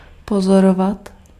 Ääntäminen
IPA: /pɔzɔrɔvat/